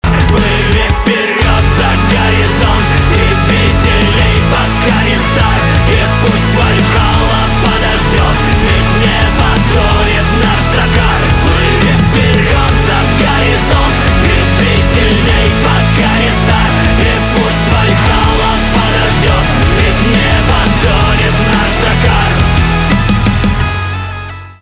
русский рок
гитара , барабаны , эпичные